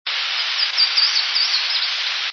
Louisiana Waterthrush
Van Campen's Glen, Delaware Water Gap. 4/8/00.  Full Waterthrush song but heavy stream sound (10kb)